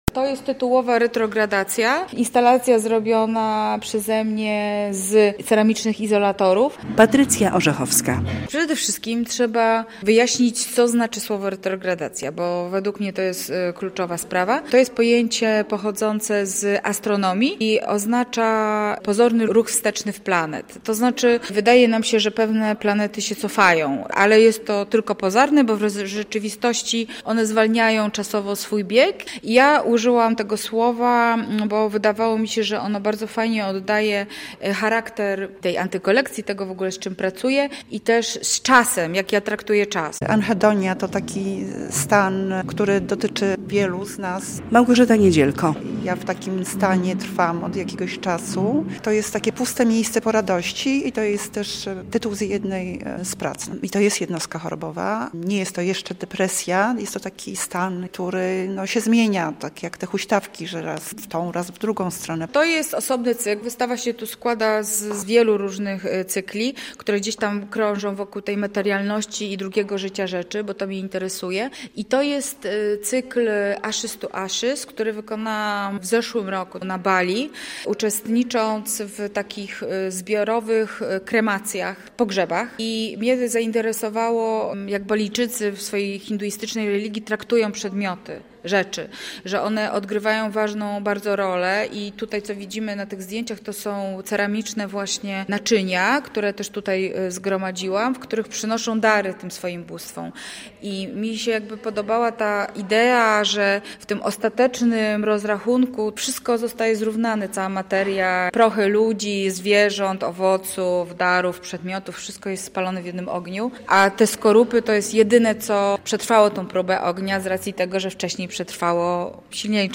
Dwie nowe wystawy w białostockiej Galerii Arsenał - relacja